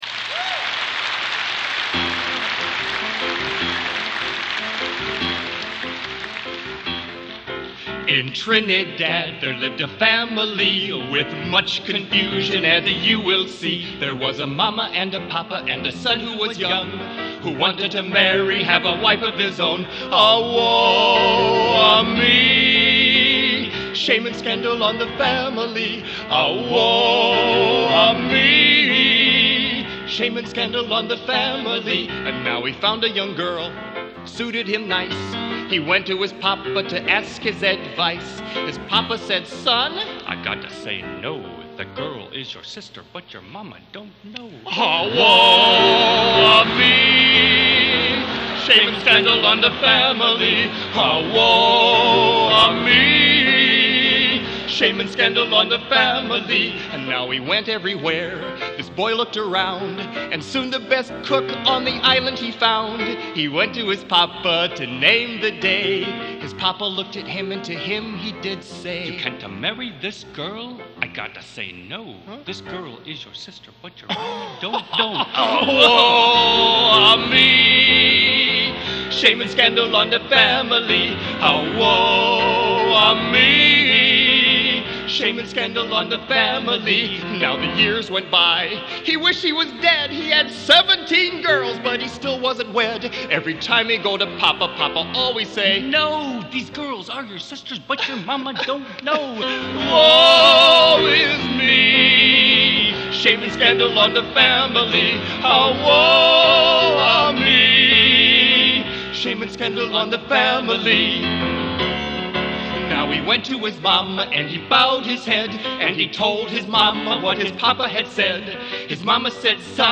Collection: Centennial Celebration Concert 1993
Genre: | Type: Director intros, emceeing |Featuring Hall of Famer